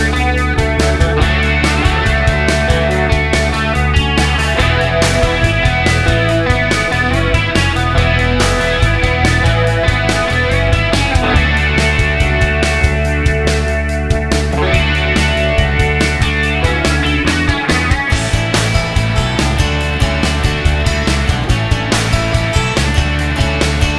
no Backing Vocals Indie / Alternative 3:42 Buy £1.50